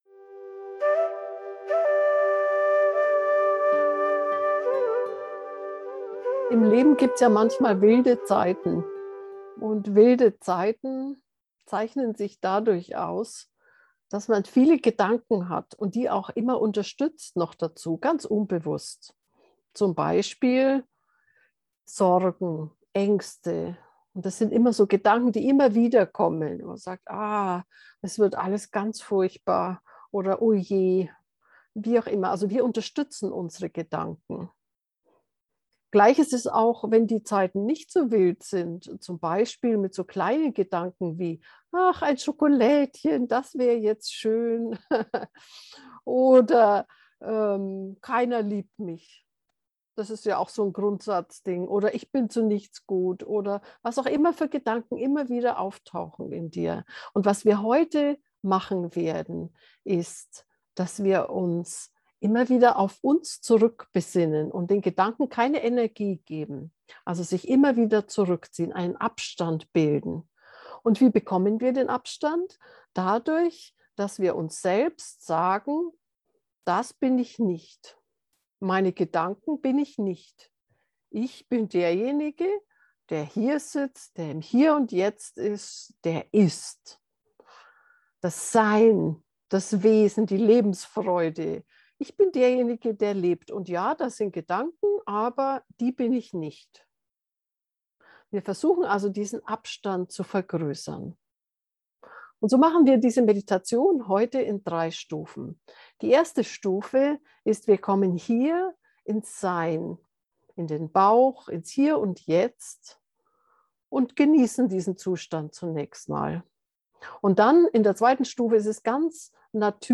In dieser geführten Meditation steigst du aus dem Sog von Gedanken aus. Zuerst gehst du in deinen Bauch, entspannst, lässt los und atmest sanft und aufmerksam in dein Zentrum innen im Bauch.
abstand-gedanken-meditation.mp3